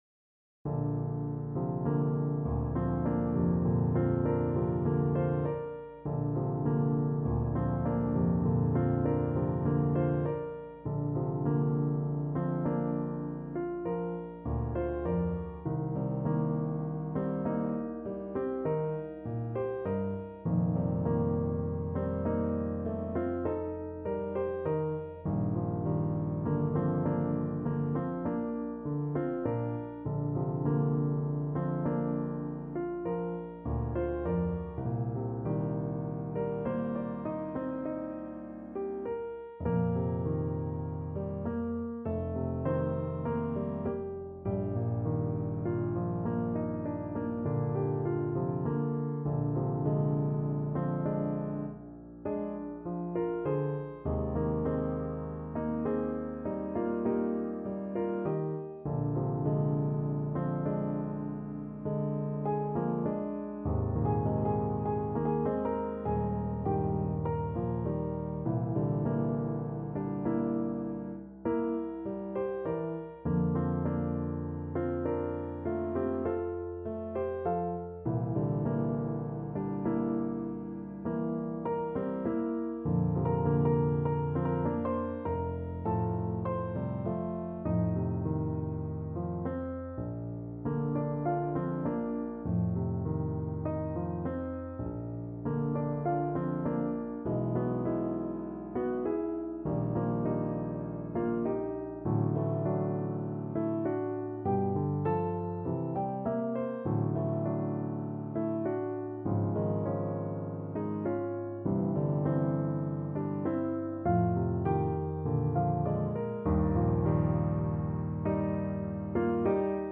Play (or use space bar on your keyboard) Pause Music Playalong - Piano Accompaniment Playalong Band Accompaniment not yet available transpose reset tempo print settings full screen
Bb major (Sounding Pitch) C major (Clarinet in Bb) (View more Bb major Music for Clarinet )
Lento =50
4/4 (View more 4/4 Music)
Classical (View more Classical Clarinet Music)